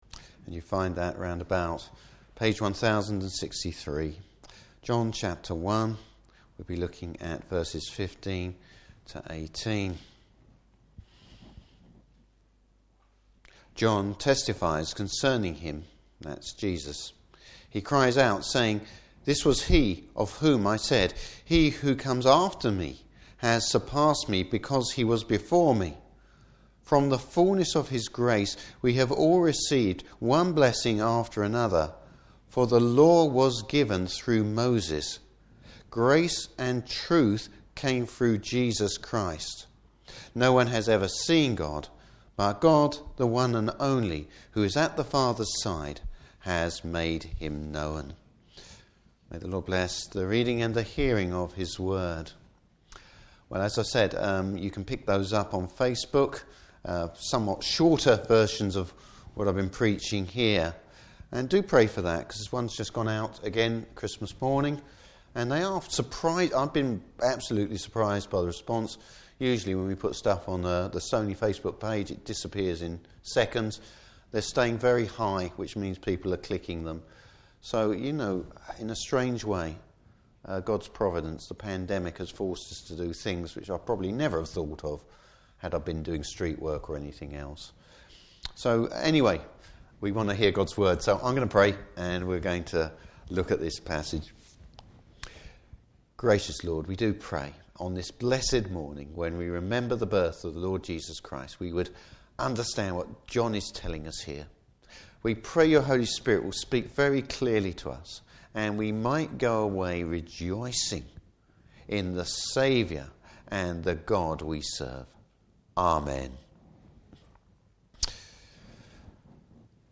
Service Type: Christmas Day Morning Service.